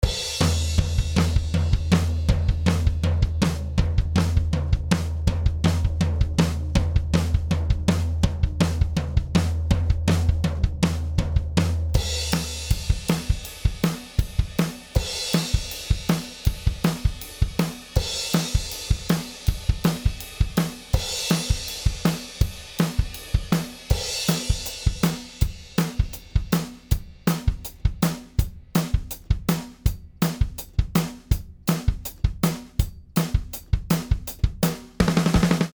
B172A 17 Drums (Limiter)